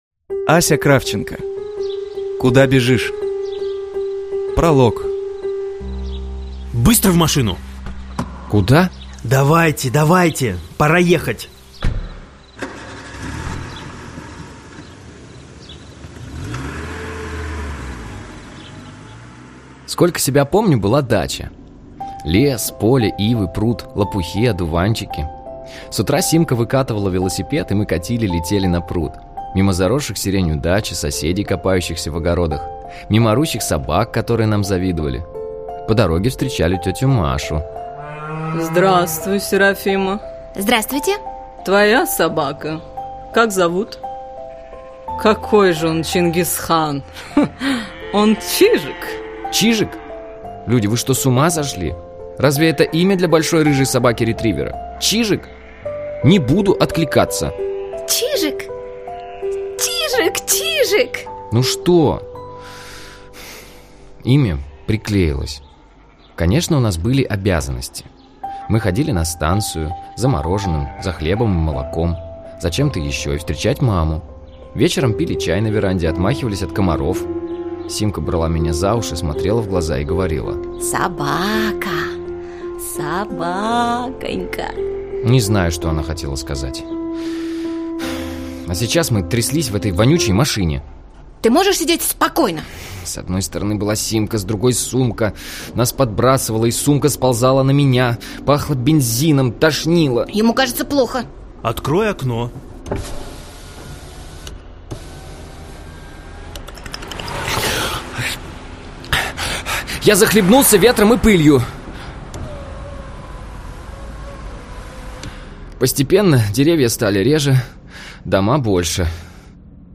Аудиокнига Куда бежишь? (аудиоспектакль) | Библиотека аудиокниг